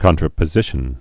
(kŏntrə-pə-zĭshən)